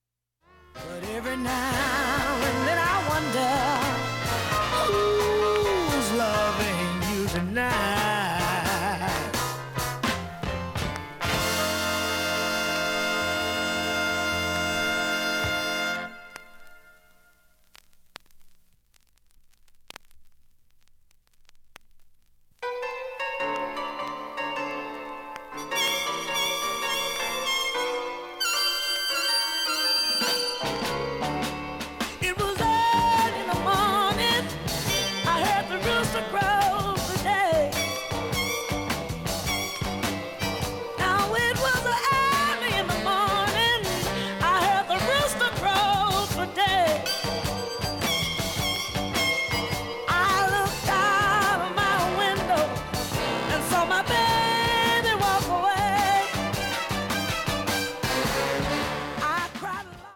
音質良好全曲試聴済み。
最初のスレ４５秒間だけプツ出るのみ。 現物の試聴（上記録音時間６０秒）できます。